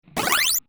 UI_SFX_Pack_61_1.wav